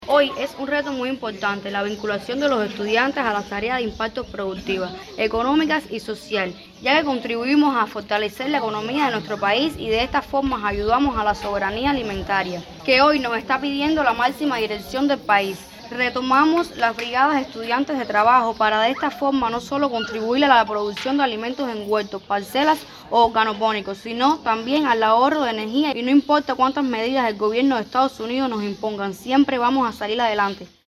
PEDRO BETANCOURT.- La escuela politécnica Leonor Pérez Cabrera, de este municipio, acogió la ceremonia de abanderamiento de las Brigadas Estudiantiles de Trabajo en el territorio.